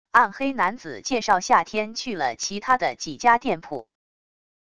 暗黑男子介绍夏天去了其他的几家店铺wav音频